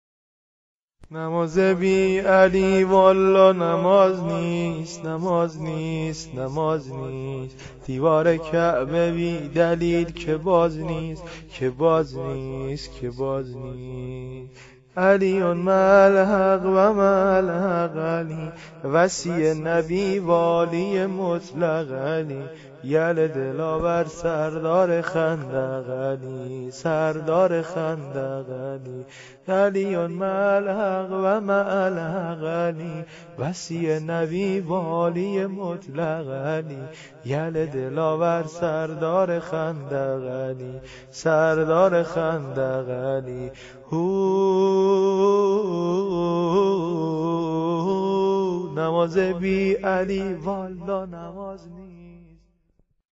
عید غدیر